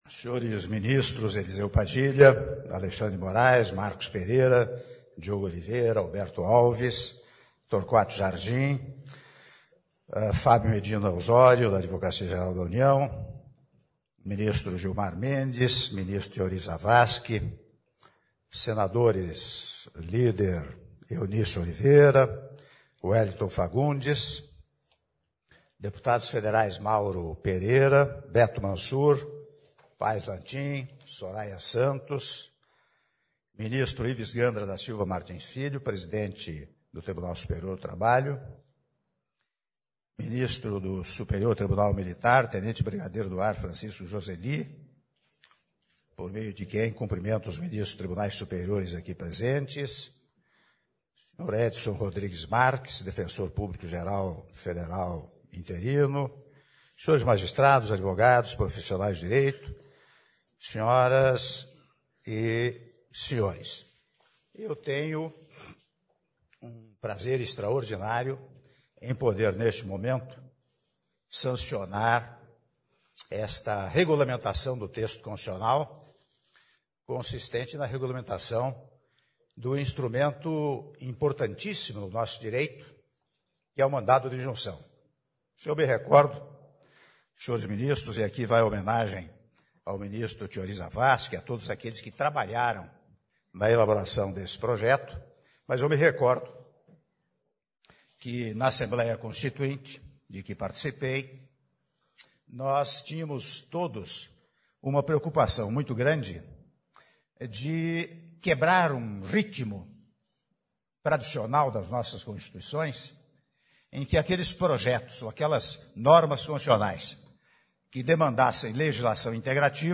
Áudio do discurso do Presidente da República em exercício, Michel Temer, na cerimônia de sanção da Lei que disciplina o processo e julgamento do mandado de injunção individual e coletivo - Brasília/DF (09min18s)